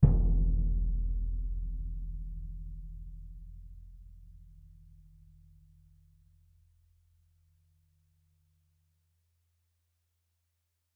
bassdrum-hit-f.mp3